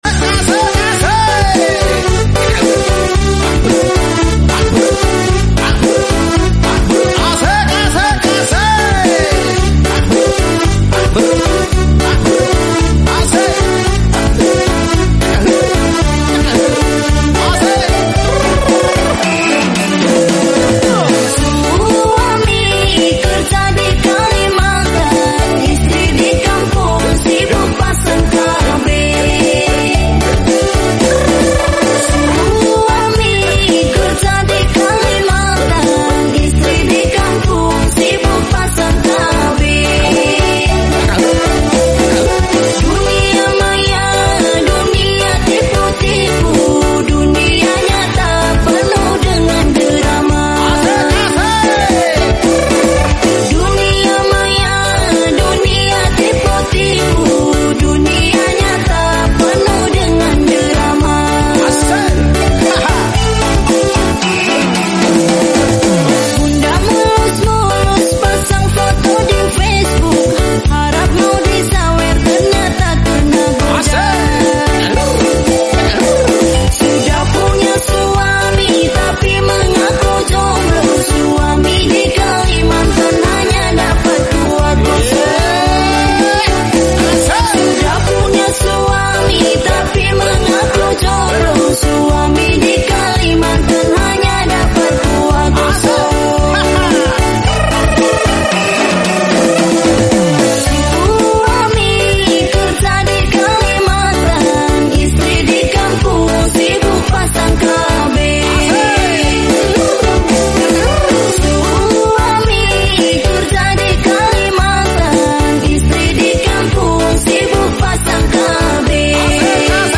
Musik DJ Siang Hari Asyik